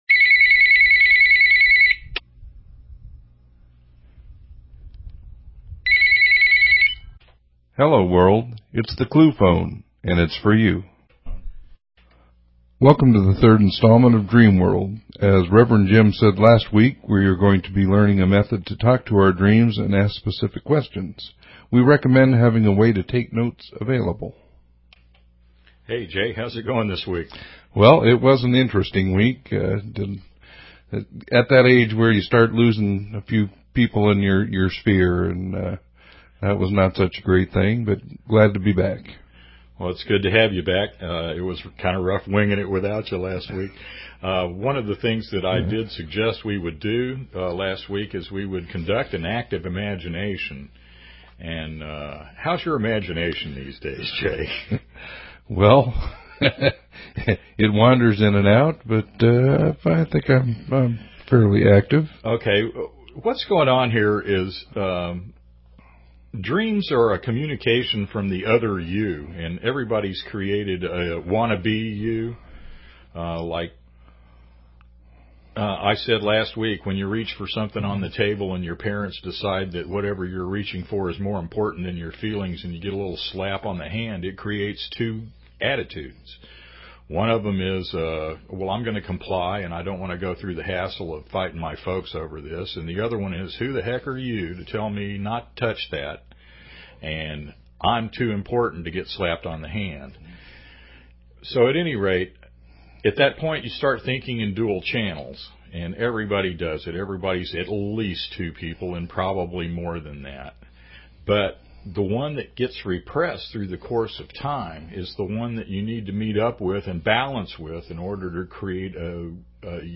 Talk Show Episode, Audio Podcast, Dream_World and Courtesy of BBS Radio on , show guests , about , categorized as